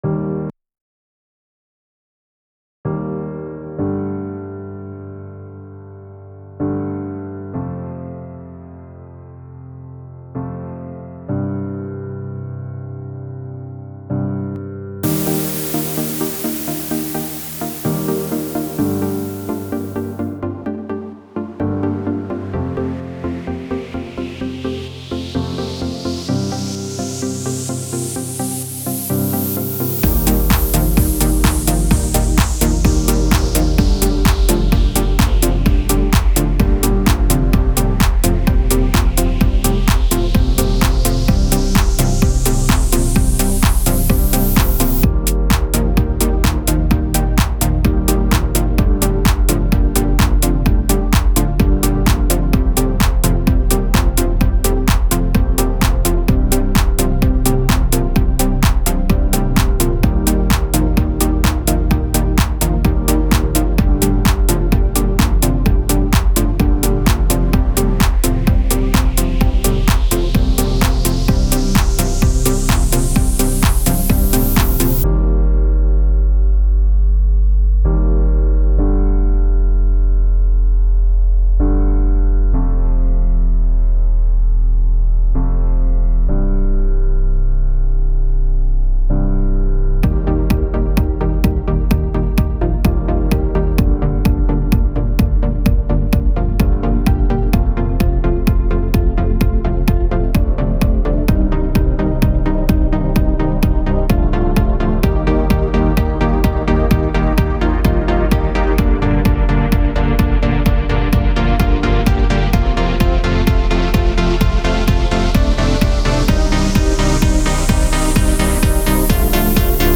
Great piano bass line, fitting very neatly with the drums. Very nice atmosphere and feel overall. If anything the drums could have been a little quieter.
Good combination of piano and synth pluck.
This song has a lot of power behind it.